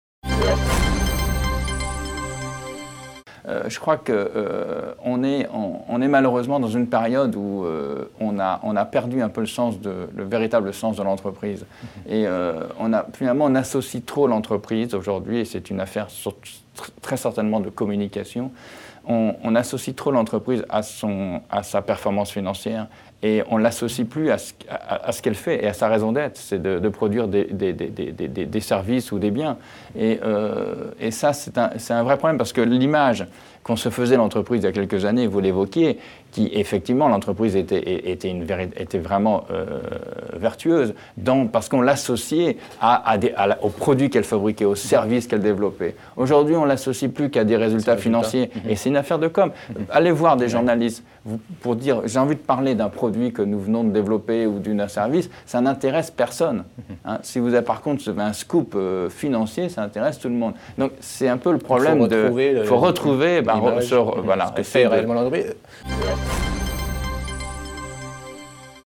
L'Économie en VO : Extraits du débat économique consacré à l'impact de la Mondialisation sur les entreprises et les salariés